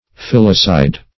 Filicide \Fil"i*cide\, n. [L. filius son, filia daughter +